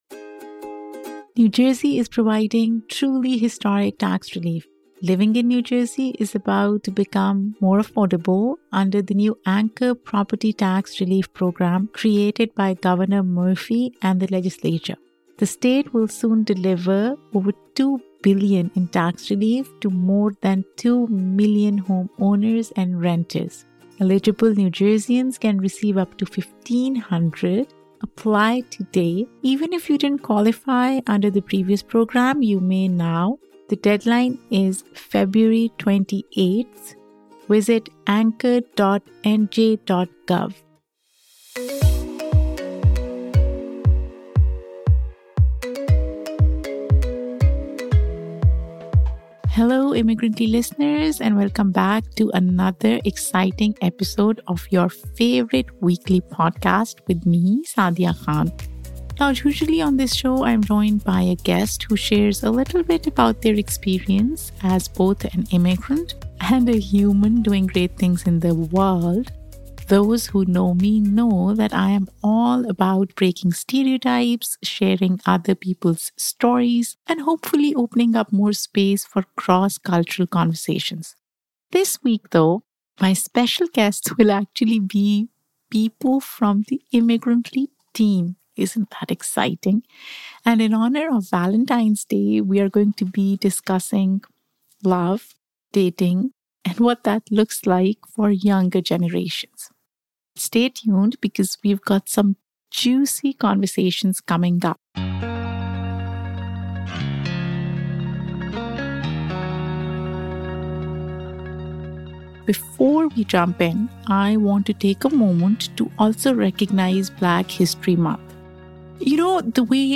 Listen to this episode to join the conversation!